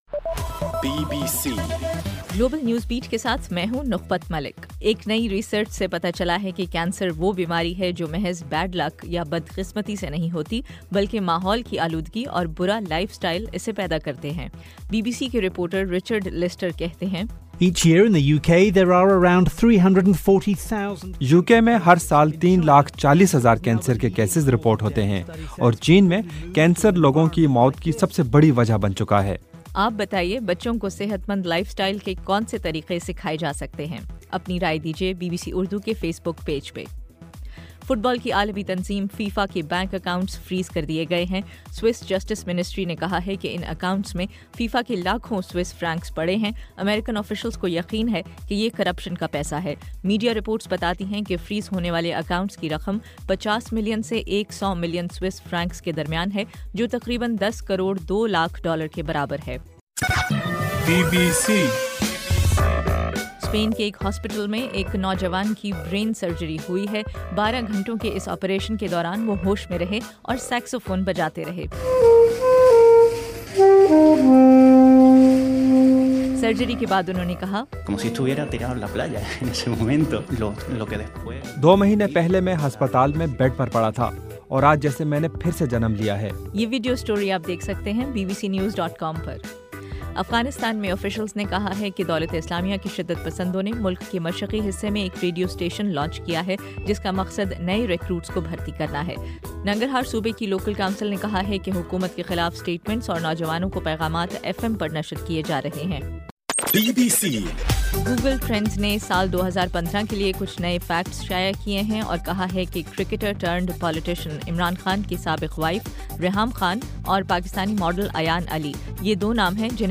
دسمبر 17 رات 10 بجے کا گلوبل نیوز بیٹ بلیٹن